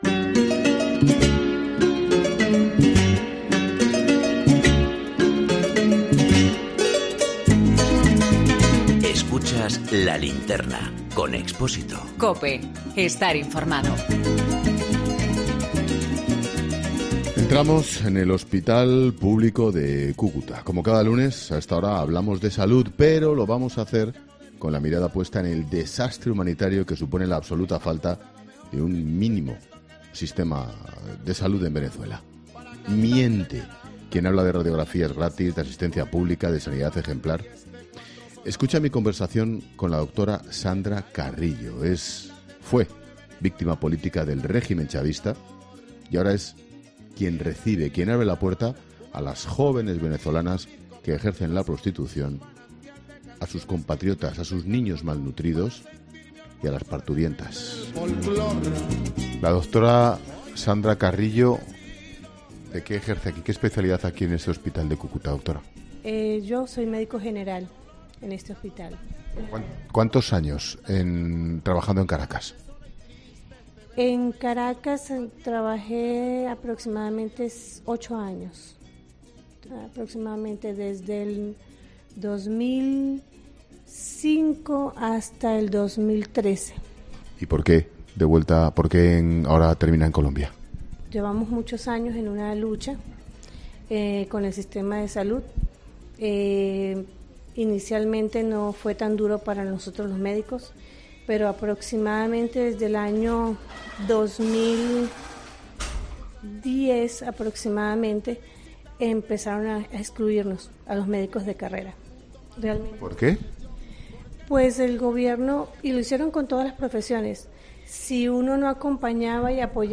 Poco menos de un mes después de la proclamación de Juan Guaidó como presidente encargado de Venezuela y de que Estados Unidos, España y otros países de la Unión Europea le hayan reconocido en el cargo, La Linterna de COPE se ha trasladado hasta Cúcuta , junto a la frontera colombiana con Venezuela.